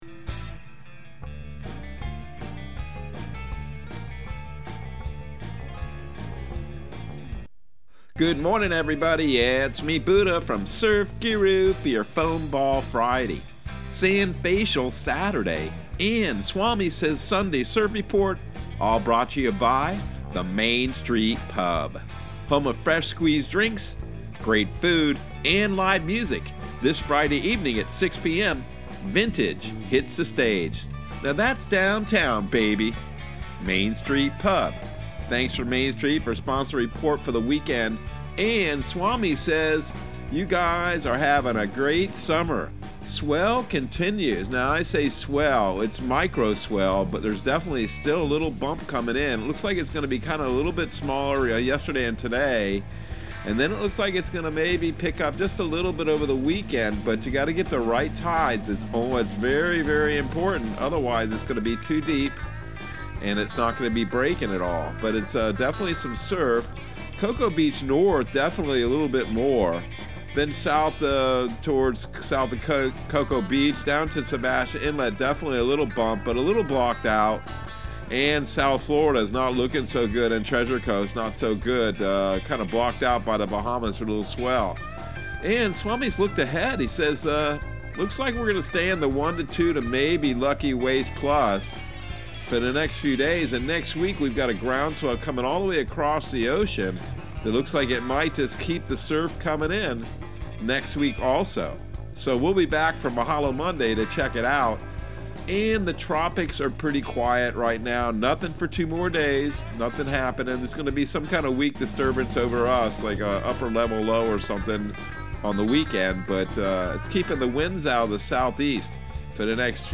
Surf Guru Surf Report and Forecast 07/22/2022 Audio surf report and surf forecast on July 22 for Central Florida and the Southeast.